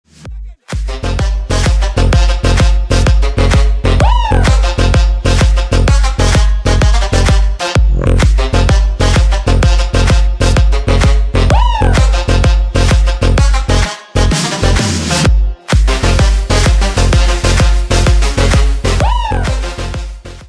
Electronica_3.mp3